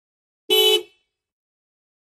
VEHICLES - HORNS 1999 TOYOTA CAMRY: Car horn, 1 toot, Toyota Camry.